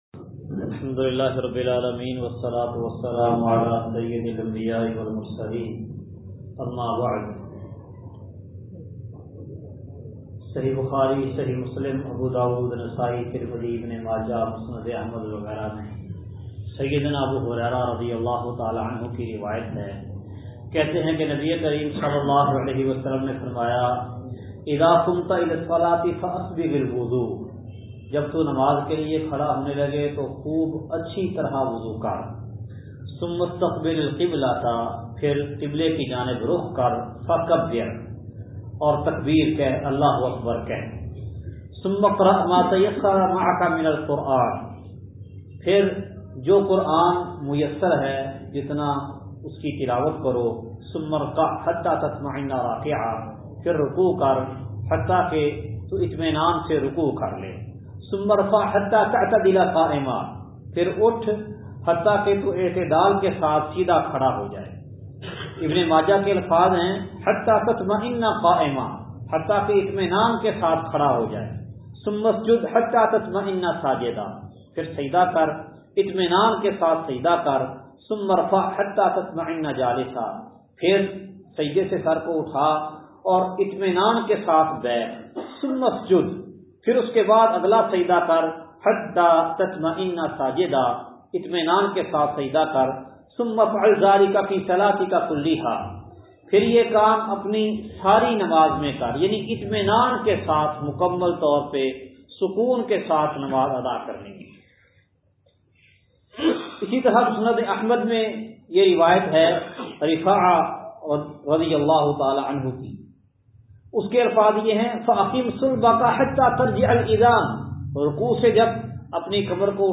درس کا خلاصہ